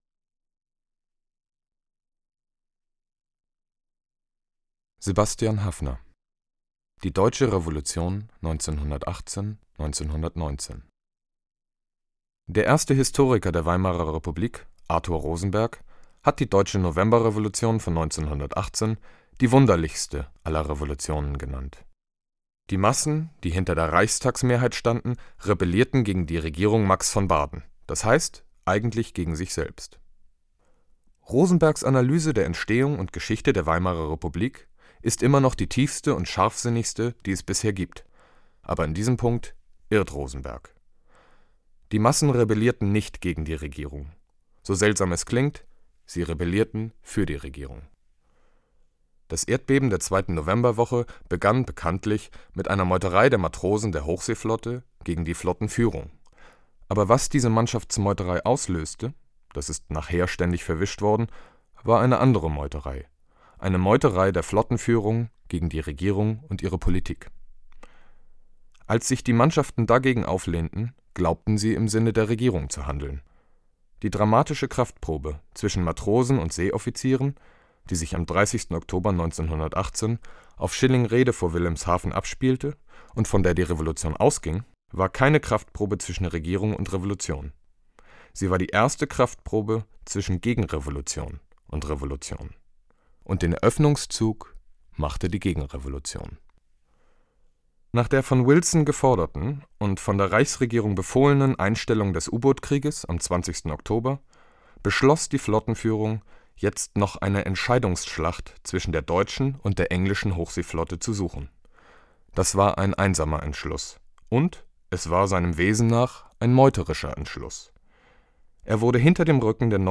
Textauszug: Die Deutsche Revolution   aus: Sebastian Haffner, Die Deutsche Revolution 1918/19